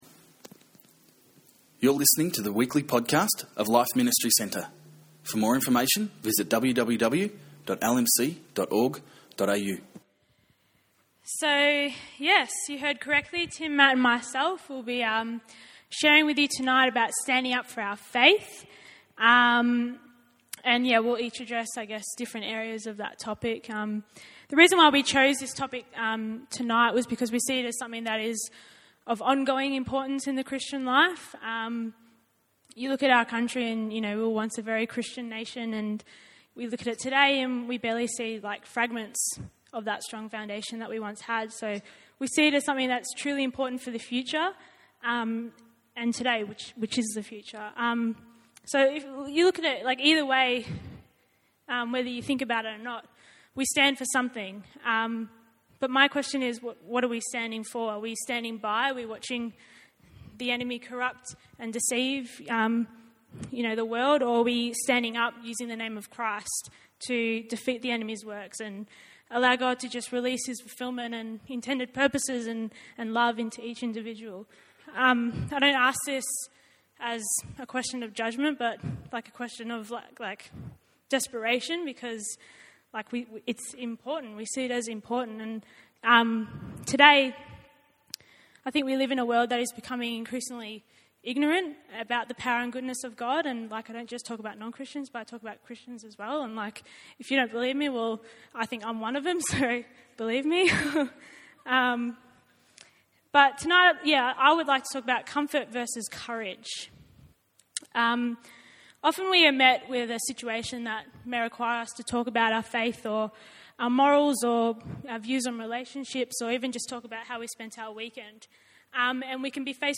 Youth Service: Standing up for your Faith